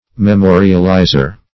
Memorializer \Me*mo"ri*al*i`zer\